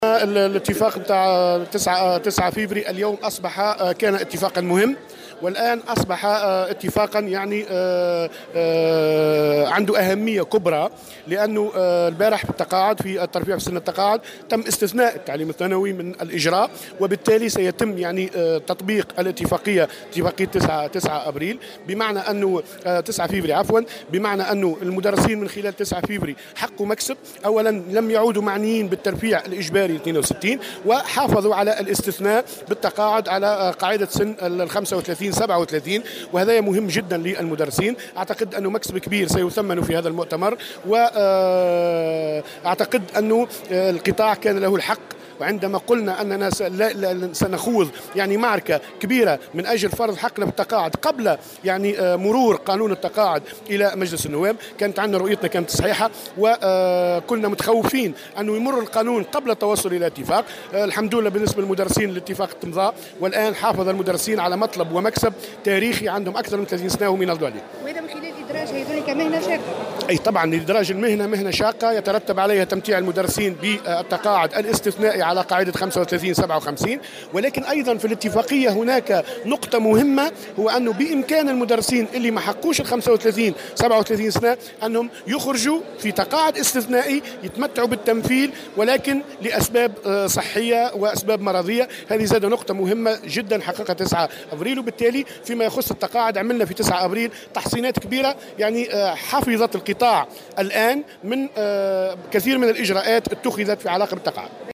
وأوضح في تصريح أمس لمراسلة "الجوهرة أف أم" على هامش انعقاد مؤتمر الجامعة العامة للتعليم الثانوي، بالحمامات، أن اتفاق 9 فيفري الماضي المتعلّق بإدراج مهنة الاساتذة كمهنة شاقة والاتفاق حول التقاعد المبكّر، كان اتفاقا مهما لأنه استثنى الأساتذة من هذا الإجراء، وفق تعبيره.